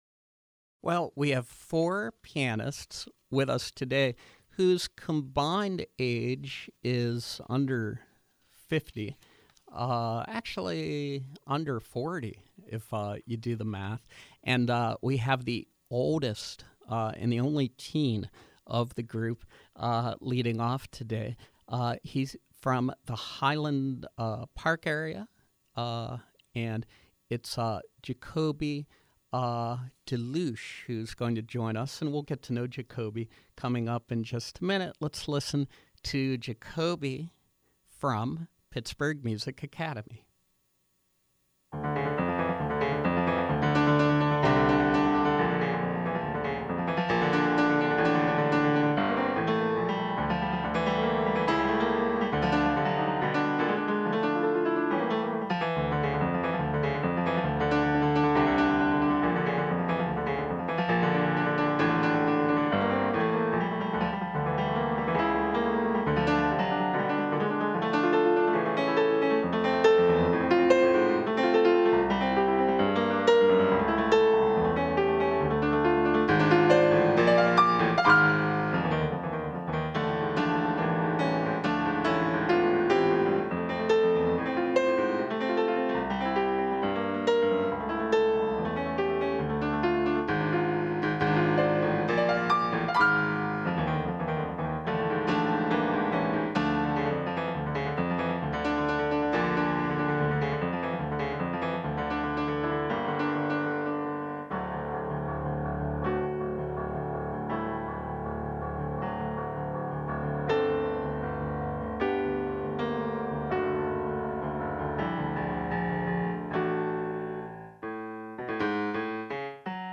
From 2/15/14: Pianists from Pittsburgh Music Academy